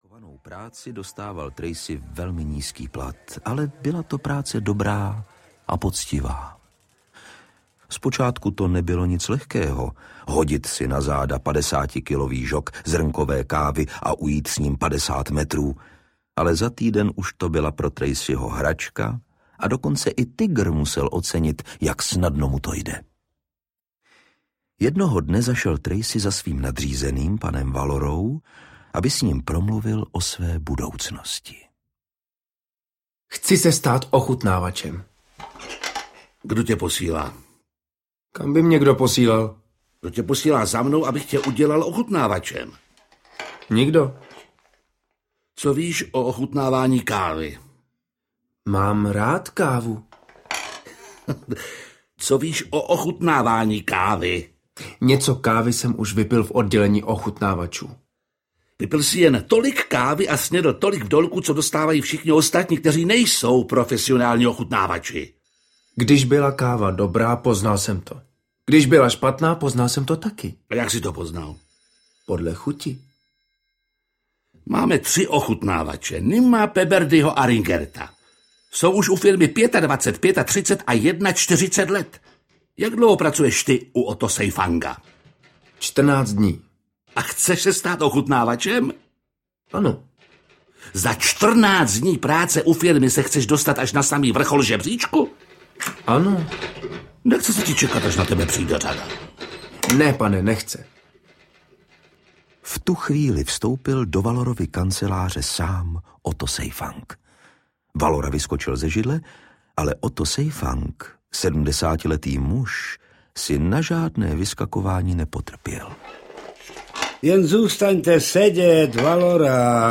Tracyho tygr audiokniha
Ukázka z knihy
• InterpretVojtěch Dyk, Jiří Lábus, Lukáš Hlavica, Josef Abrhám, Ivan Trojan